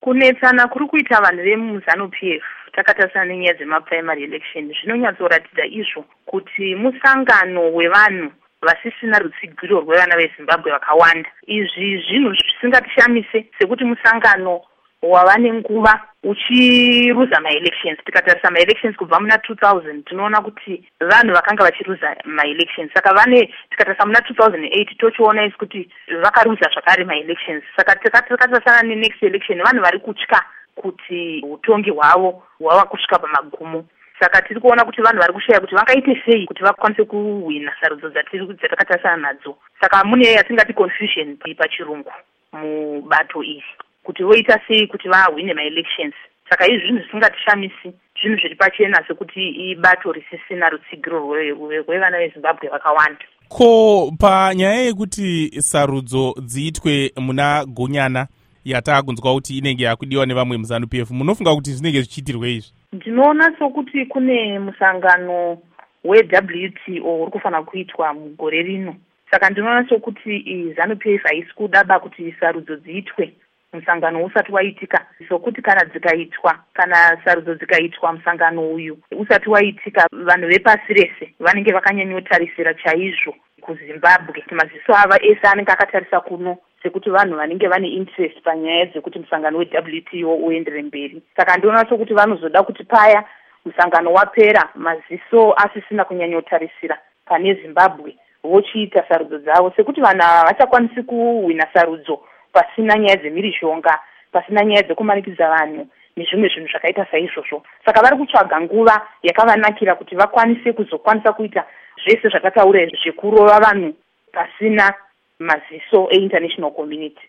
Hurukuro naVaRugare Gumbo